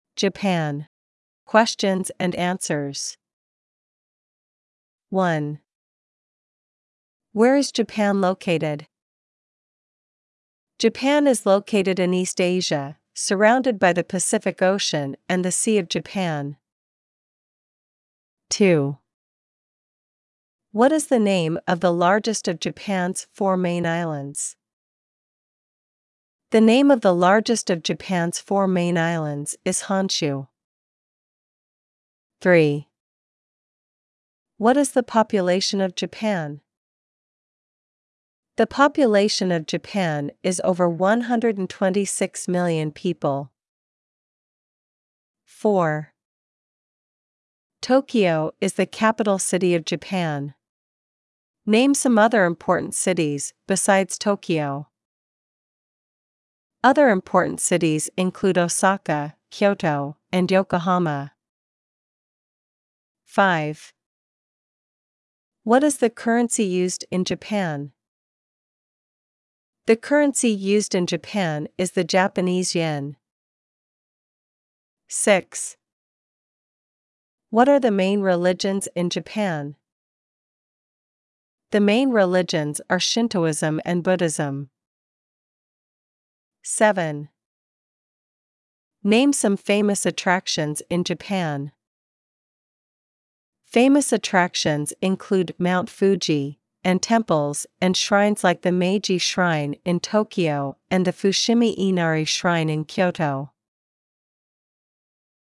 JAPAN: Questions & Answers
• a factual Q&A segment